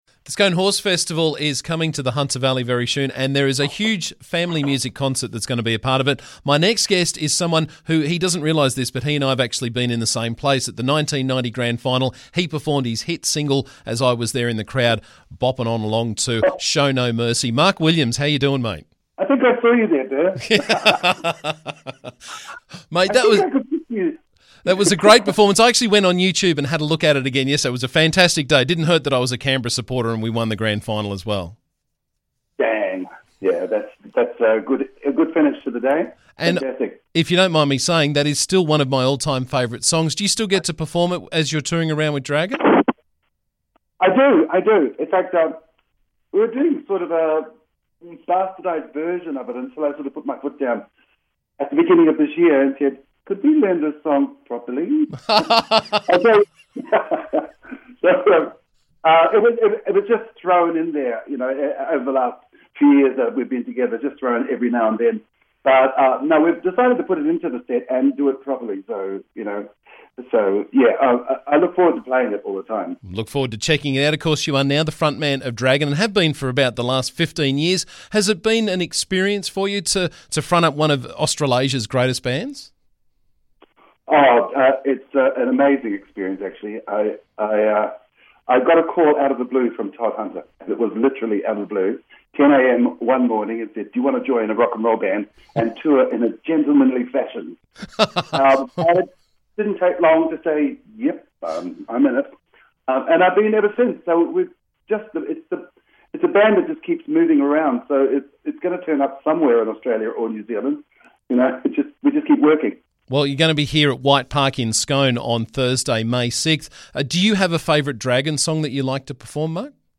Dragon front-man Mark Williams joined me to catch up and talk about their upcoming concert at White Park on May 6th.